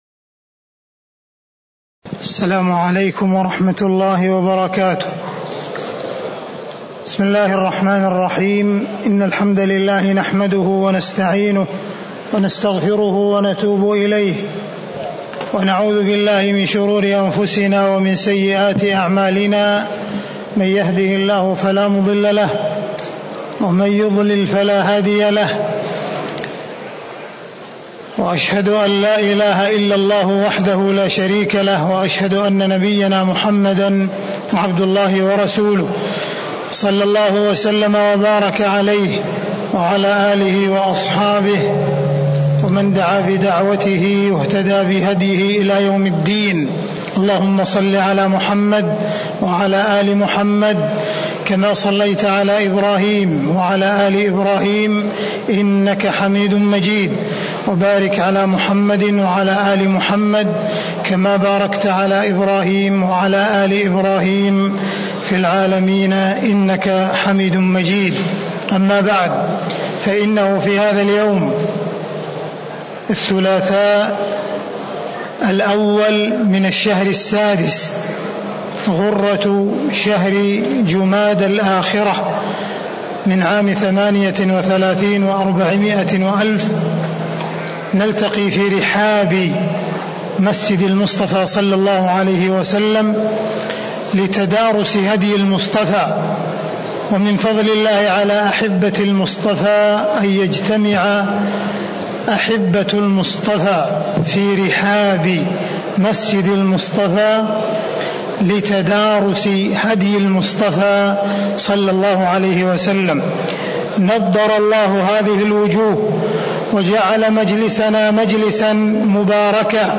المكان: المسجد النبوي الشيخ: معالي الشيخ أ.د. عبدالرحمن بن عبدالعزيز السديس